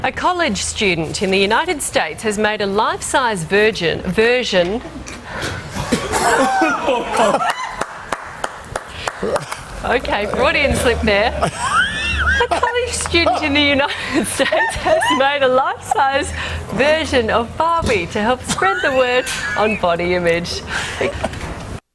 Tags: News blooper news news anchor blooper bloopers news fail news fails broadcaster fail